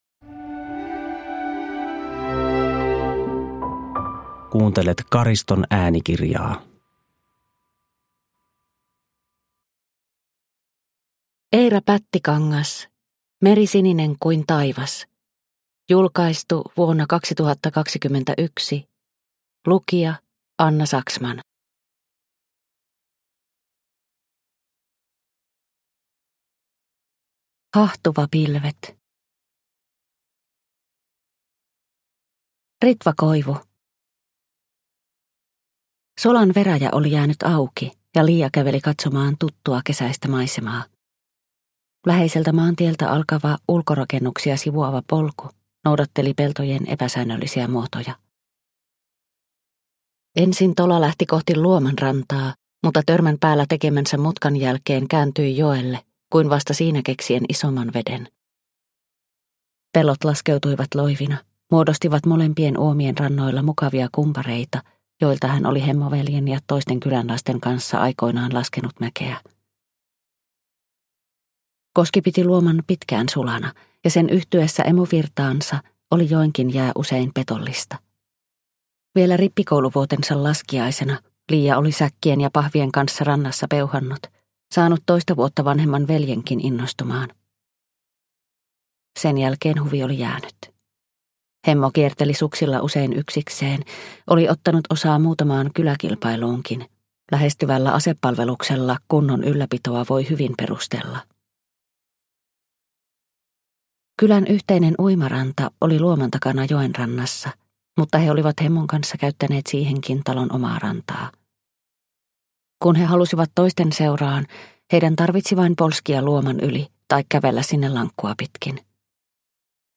Meri sininen kuin taivas – Ljudbok – Laddas ner